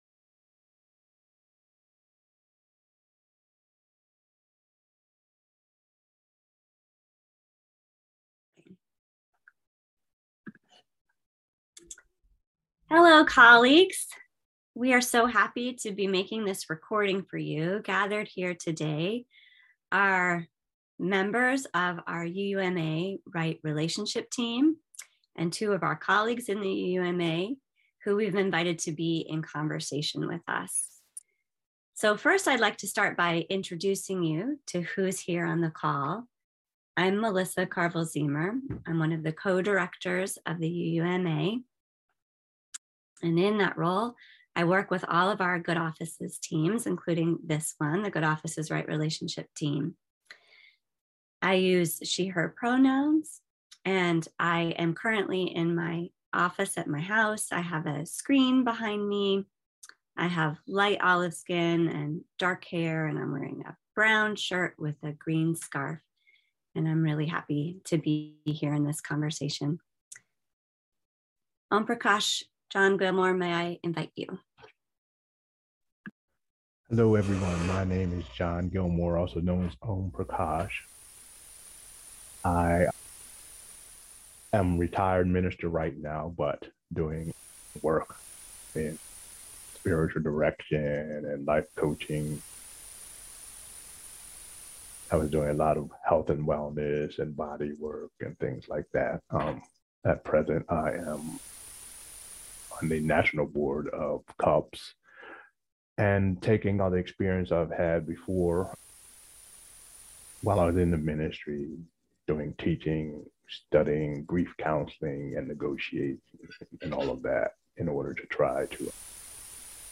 Right-Relationship-Panel-2022.mp3